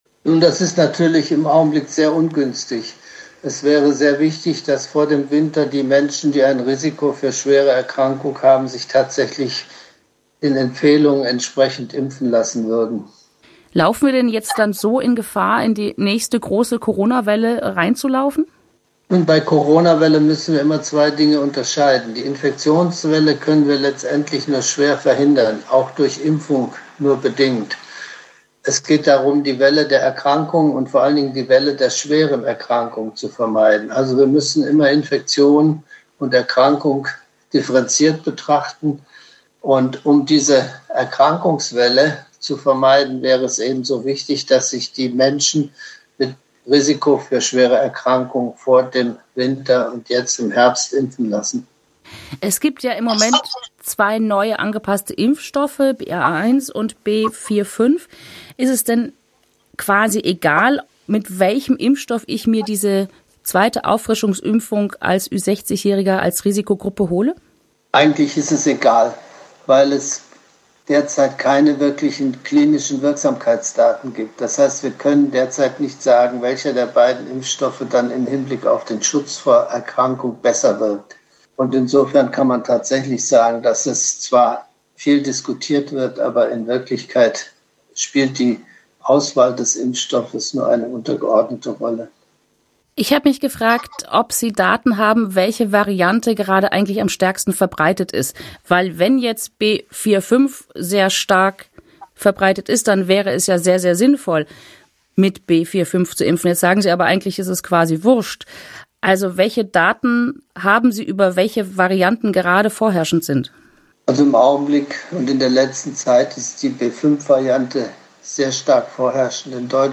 Interview mit dem Vorsitzenden der Ständigen Impfkommission